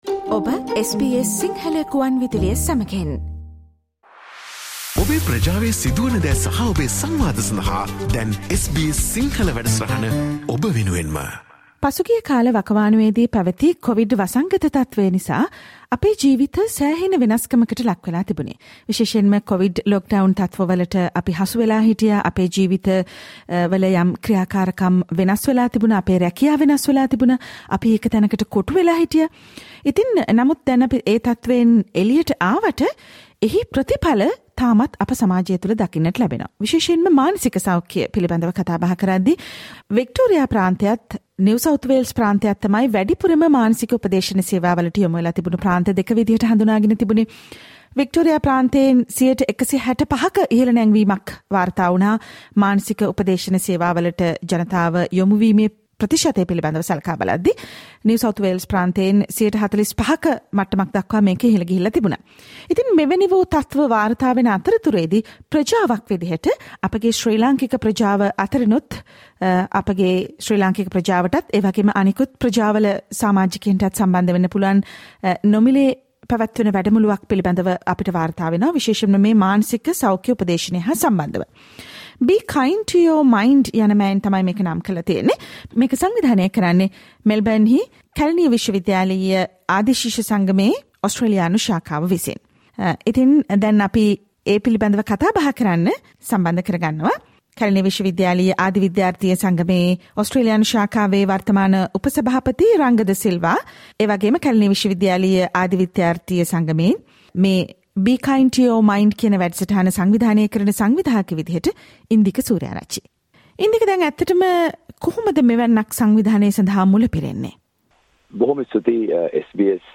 Listen to the discussion about a free mental health workshop organized by the Alumni Association of Kelaniya University which will be held this weekend, the 11th of September.